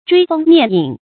追風躡影 注音： ㄓㄨㄟ ㄈㄥ ㄋㄧㄝ ˋ ㄧㄥˇ 讀音讀法： 意思解釋： 見「追風躡景」。